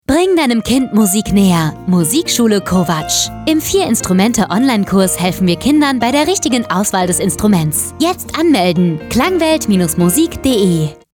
Die Musikschule im Radio
Funkspot_Musikkarussell-Musikschule-Kovac-11-Sek.mp3